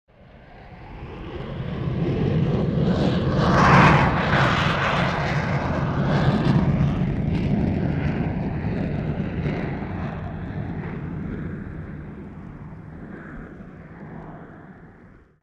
دانلود آهنگ موشک 1 از افکت صوتی حمل و نقل
جلوه های صوتی
دانلود صدای موشک 1 از ساعد نیوز با لینک مستقیم و کیفیت بالا